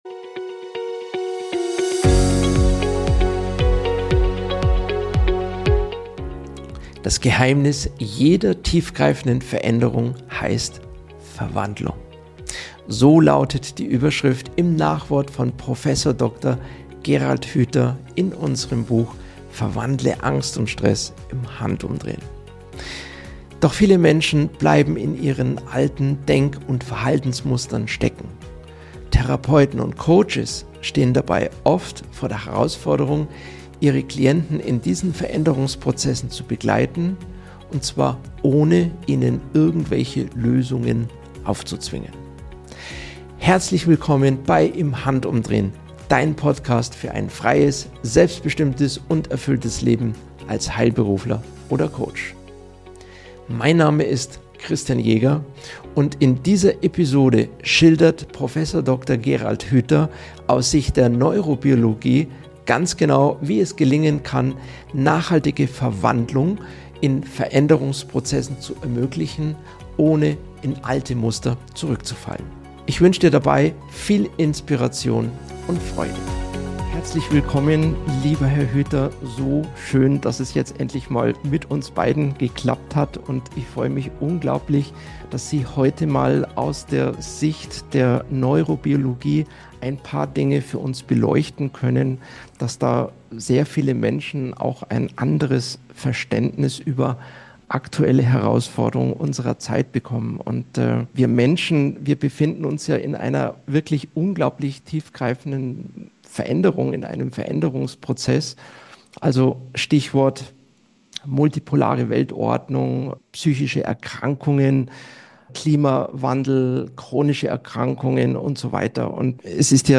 Deep Talk